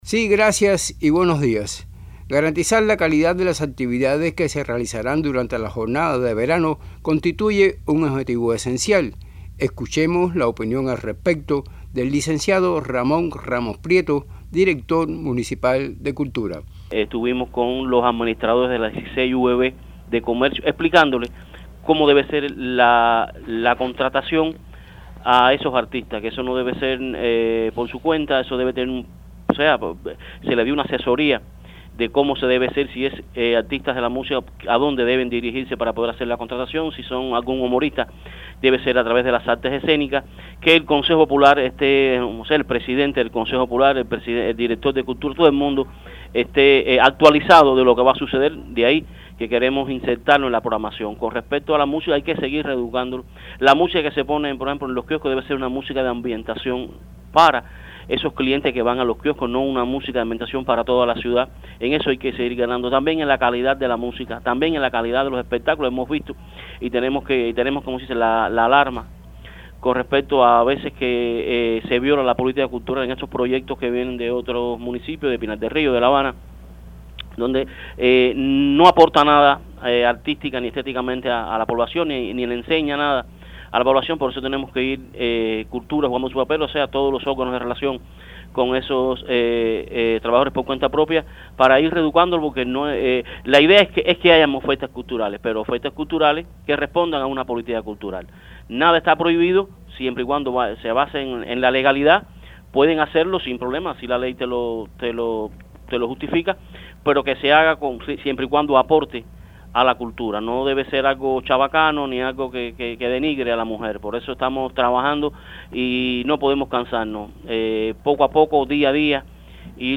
conversó al respecto con el Director Municipal de Cultura Ramón Ramos Prieto el cual realizó algunas precisiones sobre este tema.